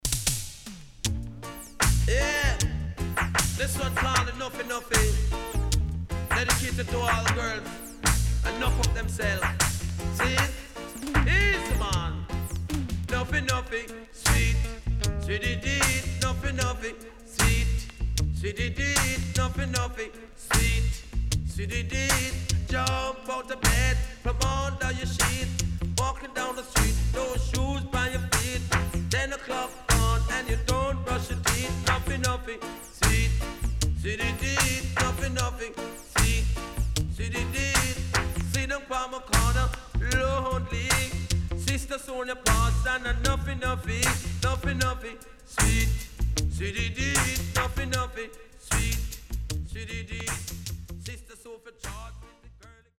HOME > DISCO45 [DANCEHALL]  >  定番DANCEHALL
SIDE A:少しチリノイズ入ります。